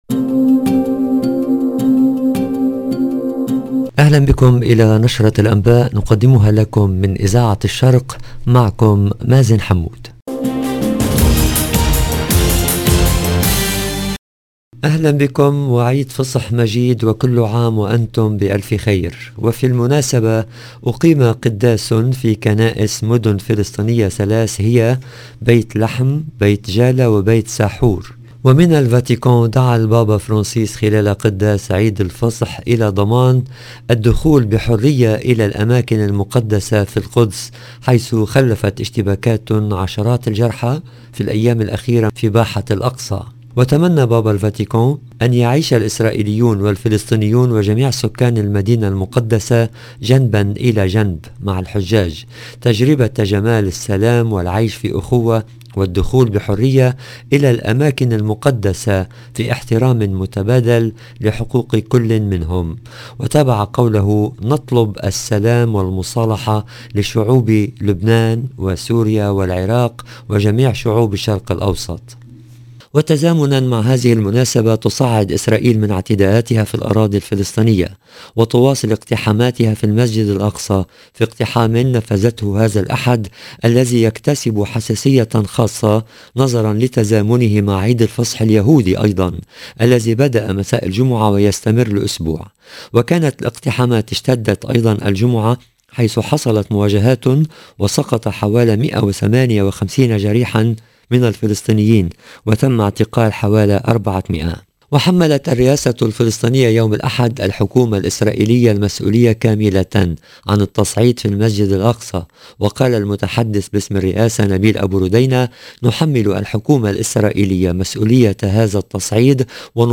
EDITION DU JOURNAL DU SOIR EN LANGUE ARABE DU 17/4/2022